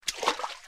Footstep on wood 2